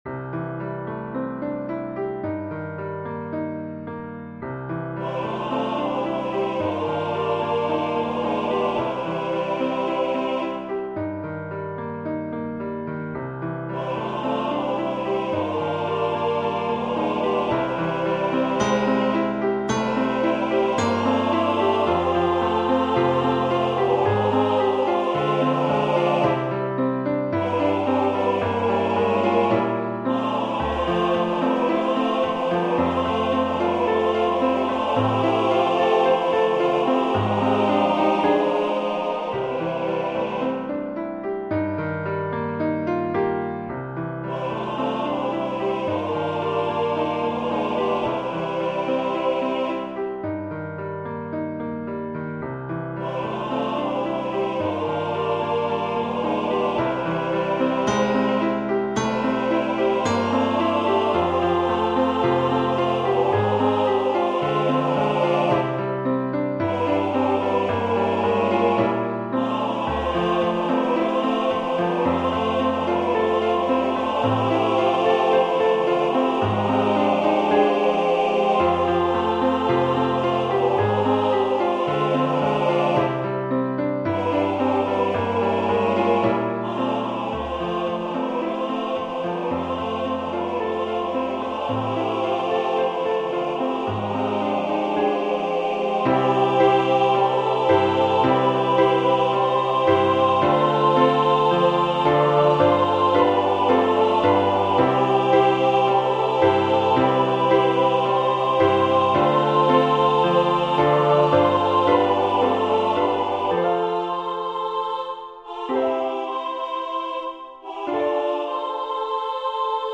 CHÓR